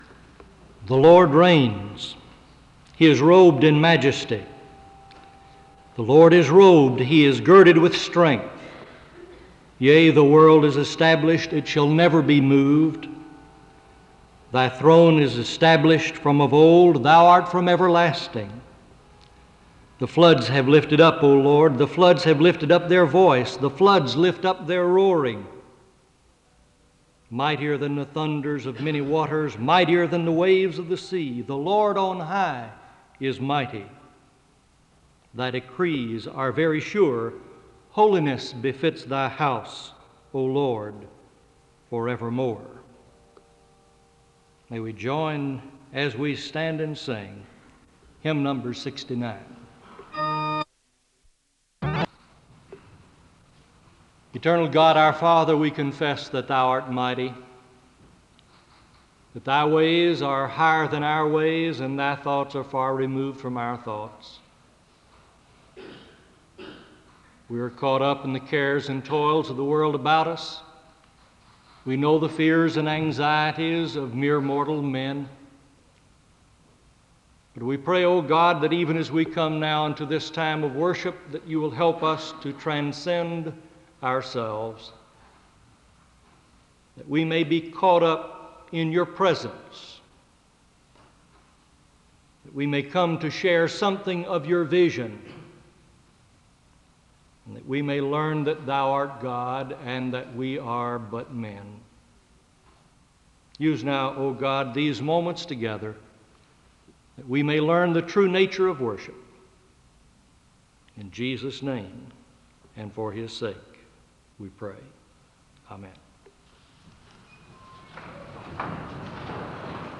The service begins with a reading of Scripture and a word of prayer (00:00-01:56). The choir leads in a song of worship (01:57-04:00).